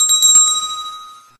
somen_bell.ogg